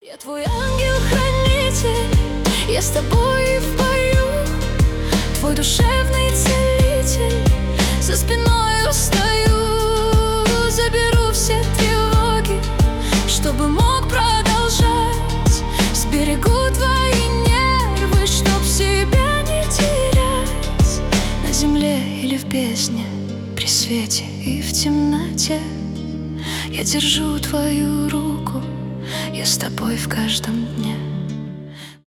поп
романтические